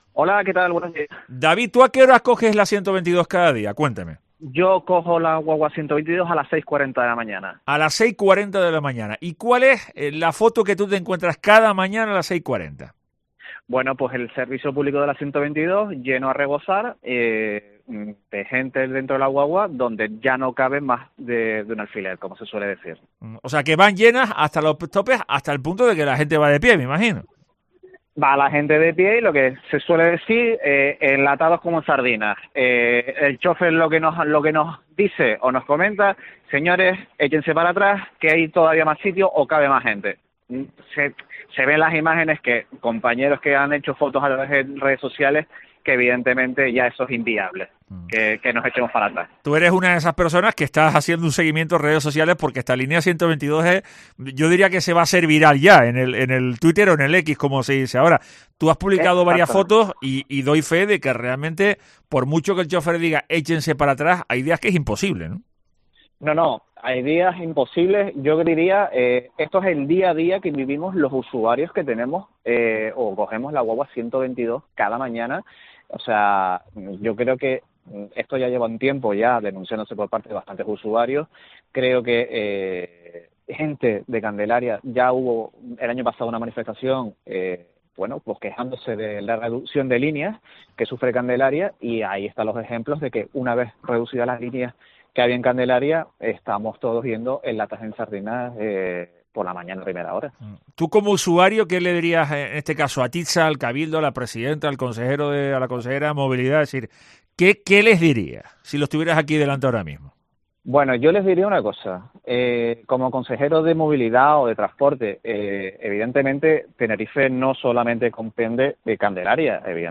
Un usuario de la línea 122 de TITSA de Candelaria a Santa Cruz: "Vamos de pie"